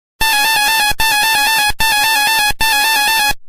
miss jingle